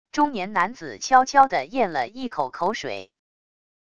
中年男子悄悄的咽了一口口水wav音频